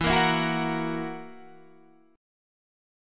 E Suspended 2nd Flat 5th